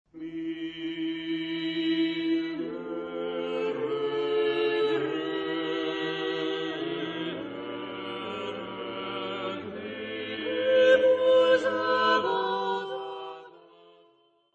Ascolta il breve frammento di polifonia di scuola fiamminga e indica se si tratta di una composizione...
polifonia.mp3